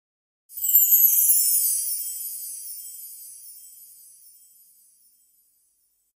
Sparkles 1.ogg